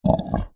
Monster_Hit3.wav